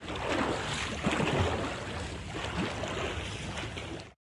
guardian_idle3.ogg